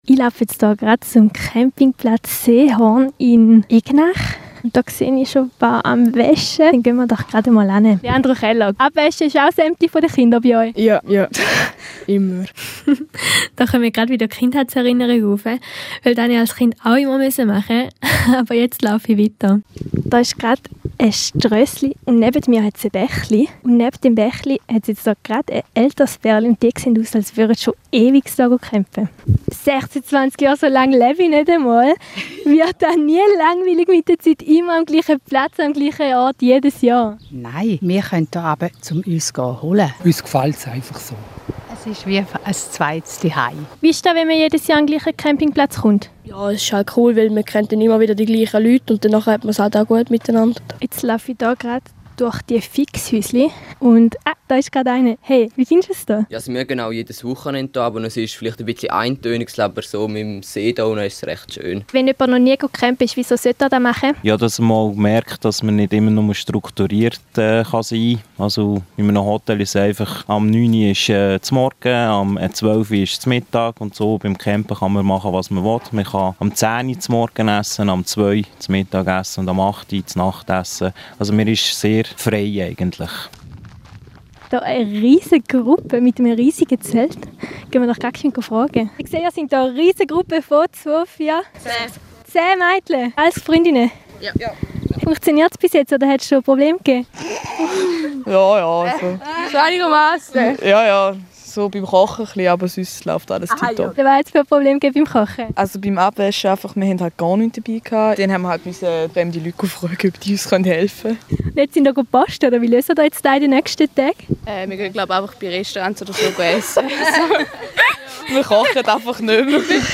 Von Campingjungfrauen über Durchreisende bis hin zu Urcampern hat es alles dabei.
Campingreportage-Egnach.mp3